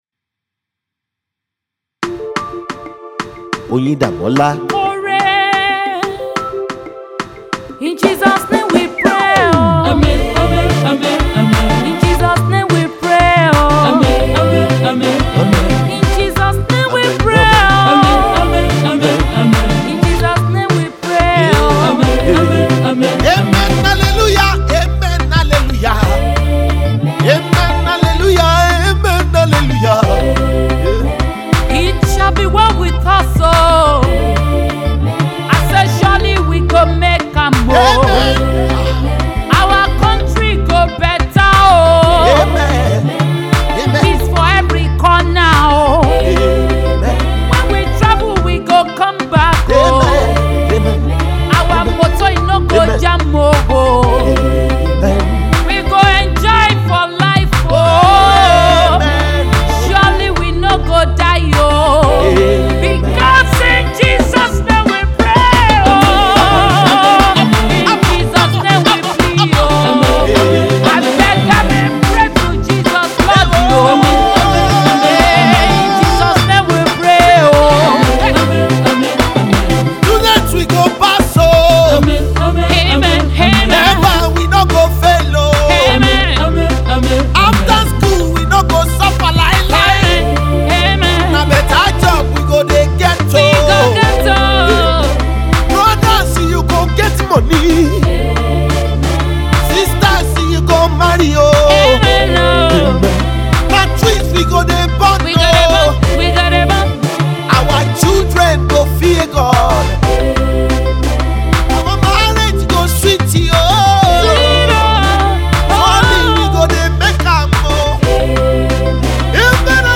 Nigerian gospel song